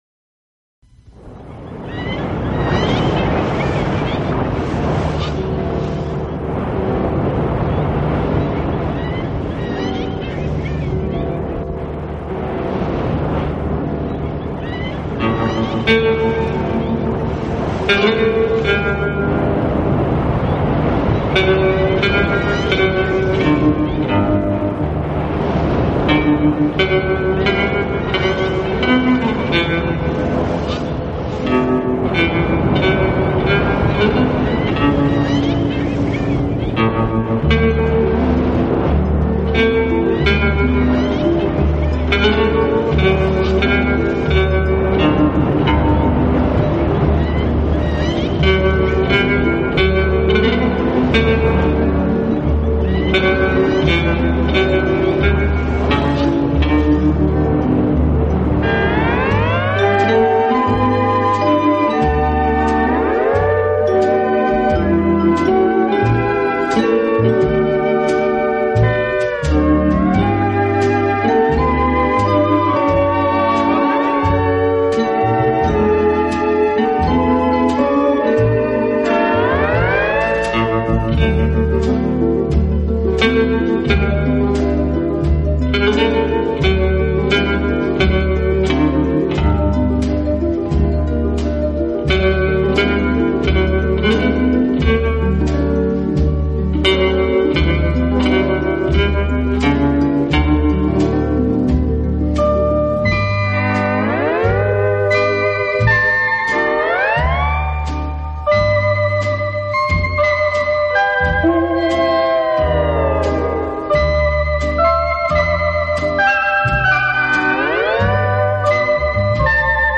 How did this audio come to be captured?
Vinyl Rip